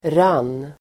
Uttal: [ran:]